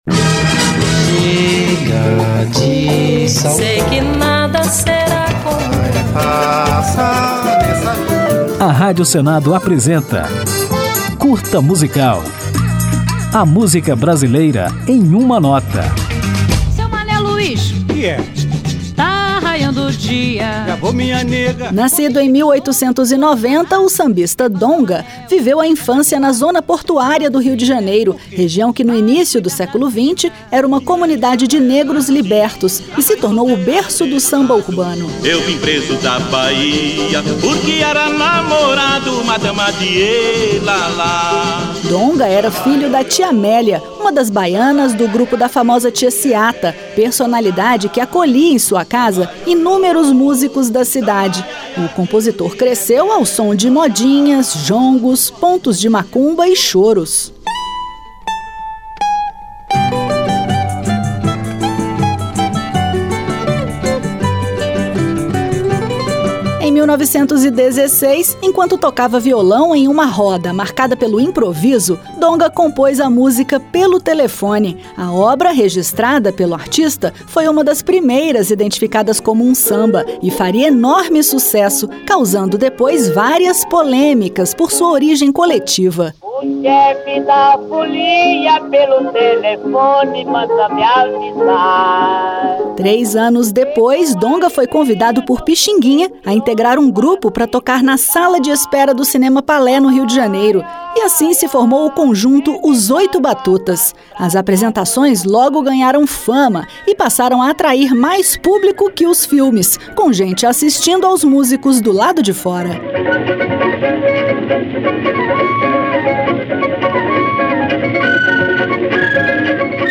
Neste Curta Musical, você vai conhecer um pouco da história deste importante sambista e ainda ouvir Pelo Telefone, numa gravação de 1956, na interpretação de Almirante.